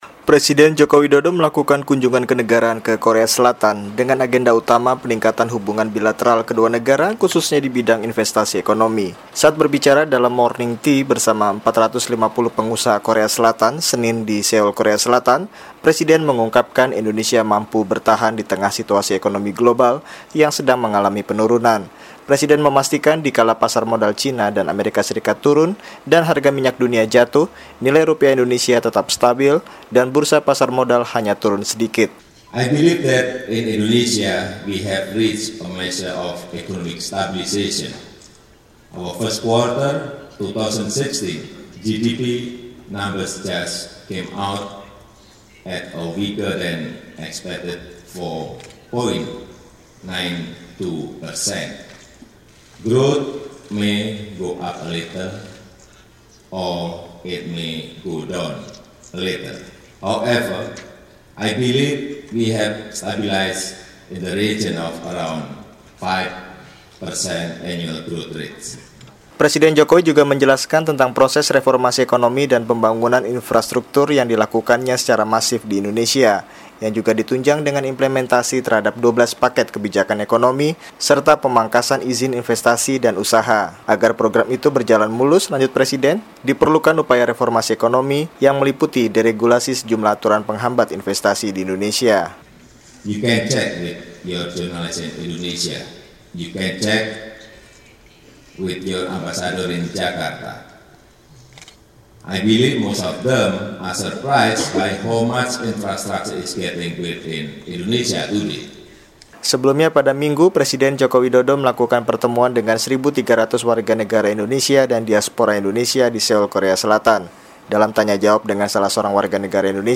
Berbicara di hadapan 450 pengusaha Korea Selatan, Presiden Jokowi menjelaskan proses reformasi ekonomi dan pembangunan infrastruktur yang sedang berlangsung di Indonesia.